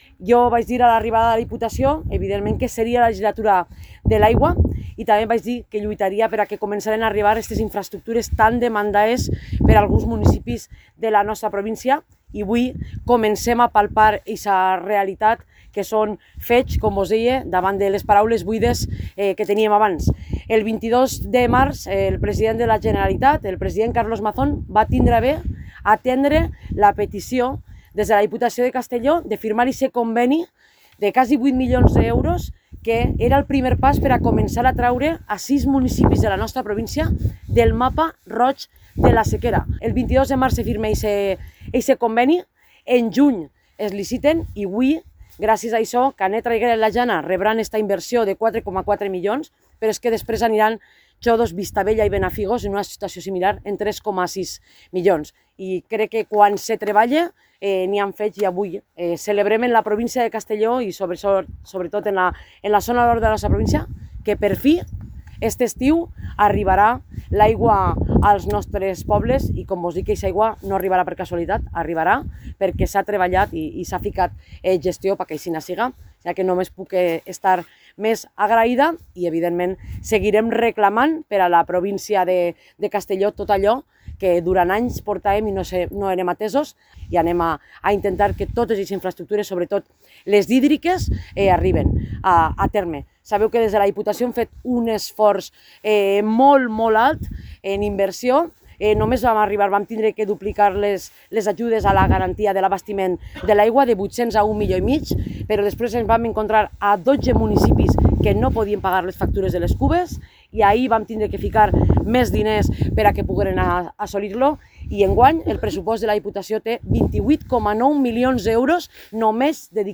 Presidenta-de-la-Diputacion-Marta-Barrachina-obras-abastecimiento-agua-potable-.mp3